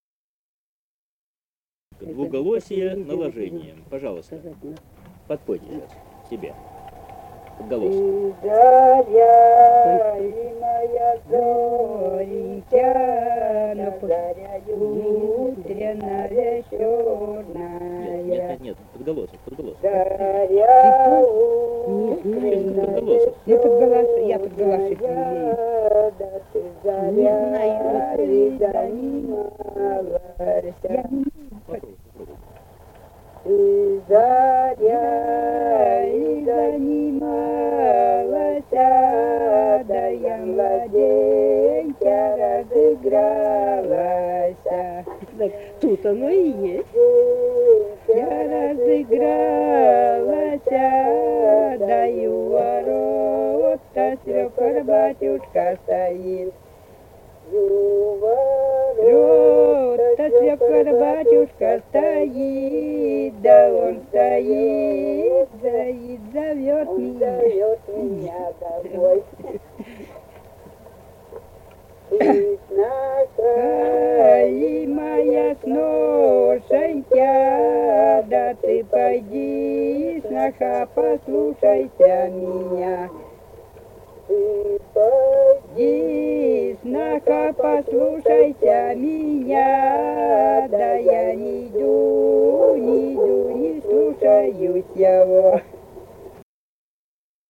Русские песни Алтайского Беловодья 2 [[Описание файла::«Ты заря ли, моя зоренька», «лужошная», последняя песня; поют, когда расходятся с гуляния.